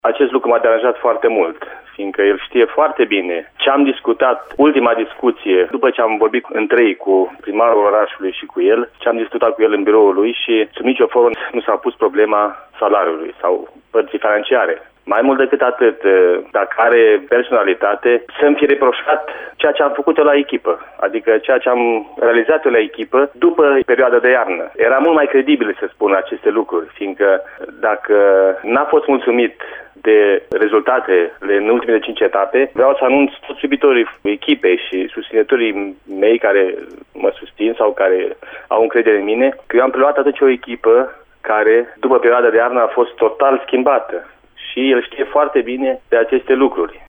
Dorinel Munteanu a vorbit, pentru Radio Reșița, după despărțirea de CSM Reșița, anunțată la finele săptămânii trecute.